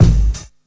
07_Kick_16_SP.wav